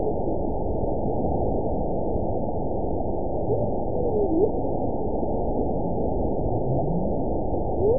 event 921840 date 12/19/24 time 22:33:41 GMT (5 months, 4 weeks ago) score 9.07 location TSS-AB01 detected by nrw target species NRW annotations +NRW Spectrogram: Frequency (kHz) vs. Time (s) audio not available .wav